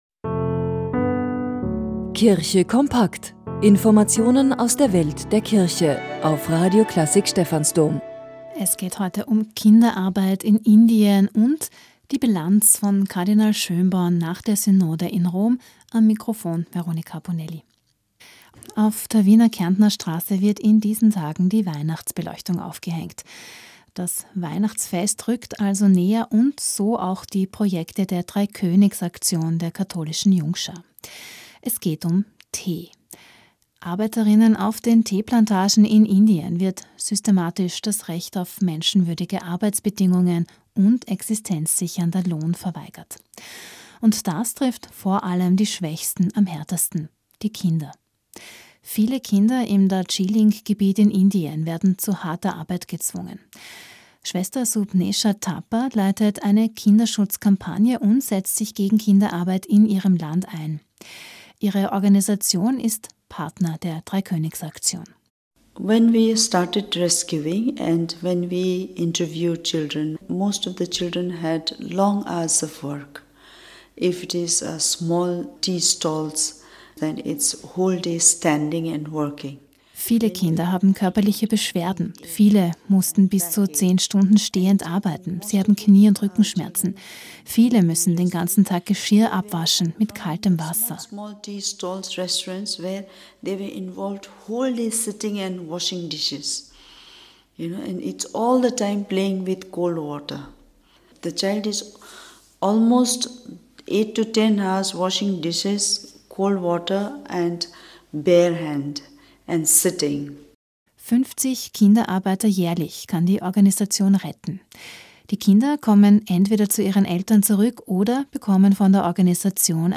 Radio Klassik Stephansdom (Radiobeitrag).